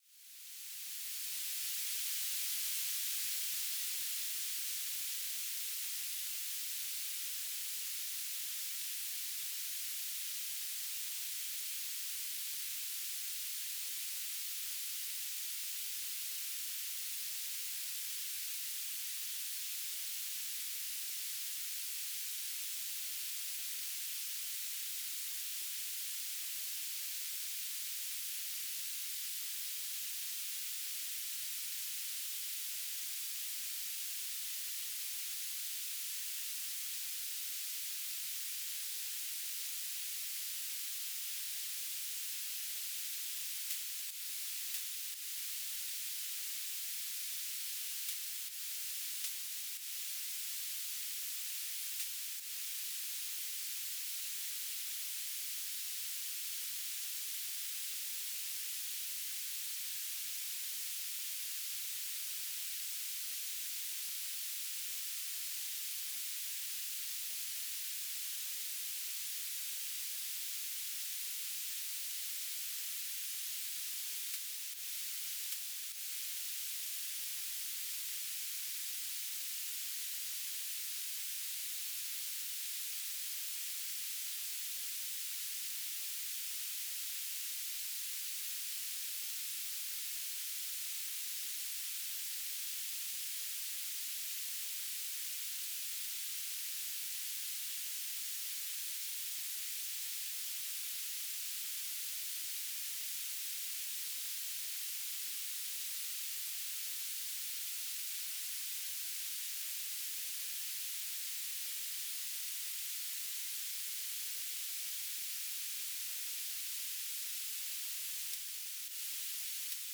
"transmitter_mode": "BPSK",